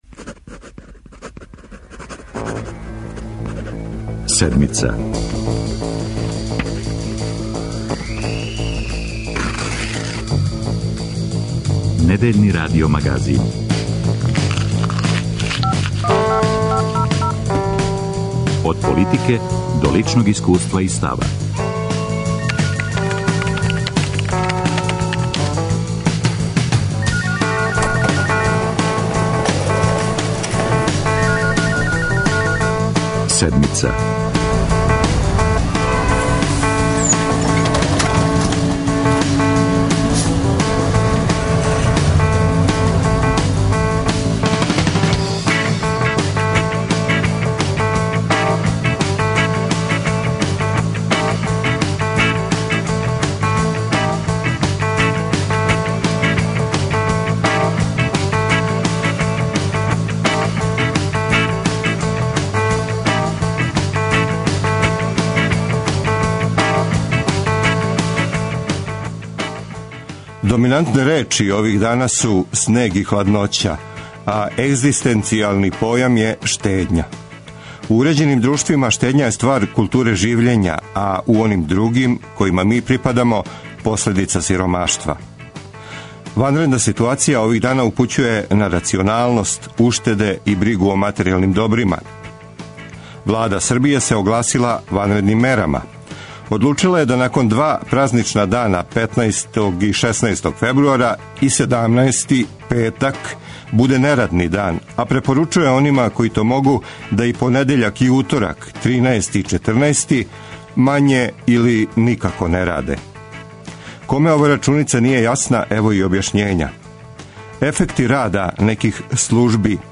Да ли ће Висока пећ и даље грејати Смедерево, пита репортер Седмице раднике железаре.